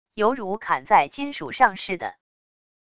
犹如砍在金属上似的wav音频